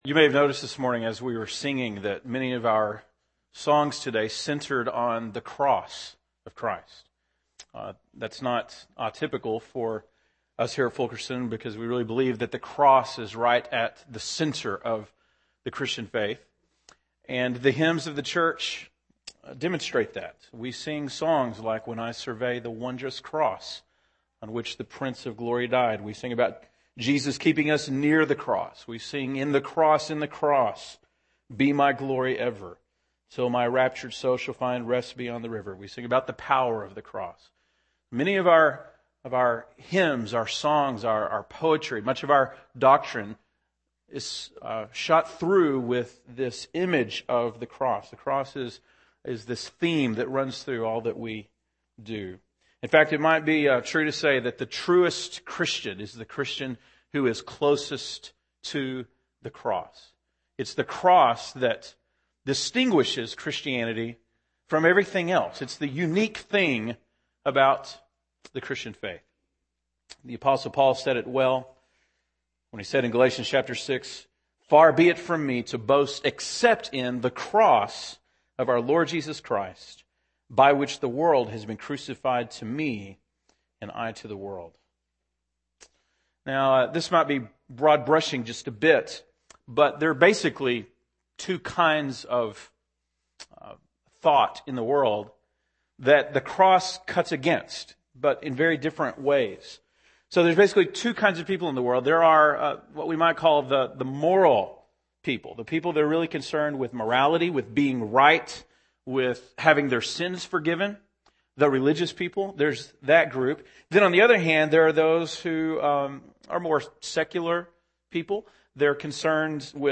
March 28, 2010 (Sunday Morning)